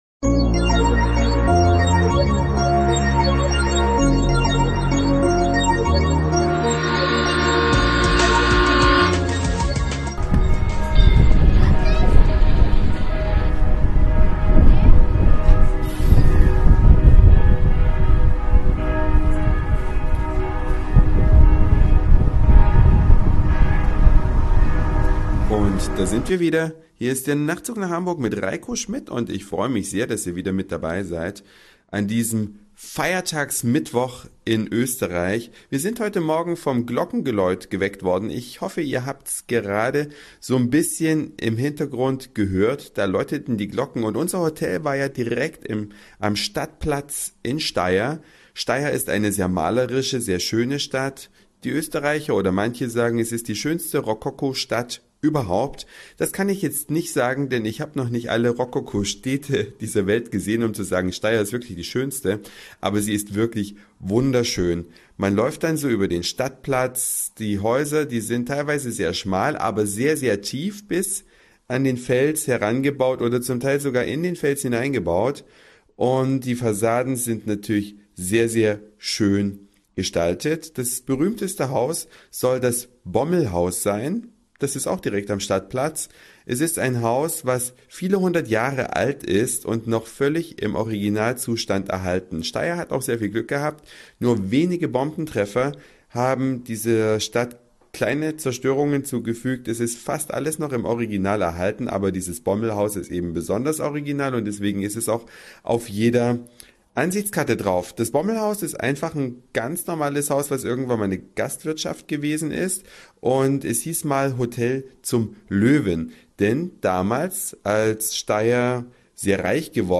Eine Reise durch die Vielfalt aus Satire, Informationen, Soundseeing und Audioblog.
Schöne Häuserfassaden, malerische Gassen und Glockengeläut zum